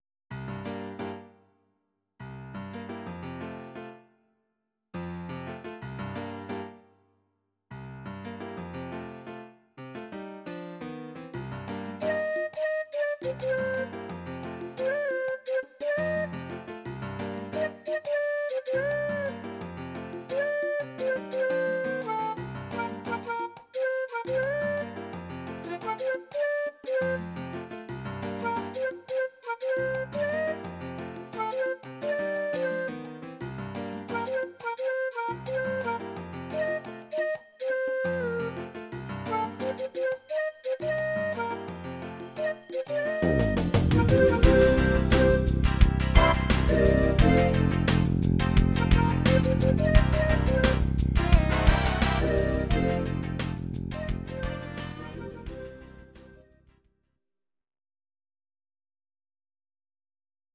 Audio Recordings based on Midi-files
Pop, Musical/Film/TV, 2000s